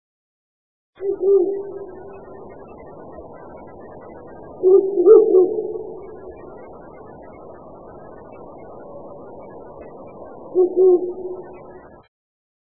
〔フクロウ〕ゴロスケホッホッ／樹林などに棲息，普通・留鳥，50p，雌雄同色
fukuro.mp3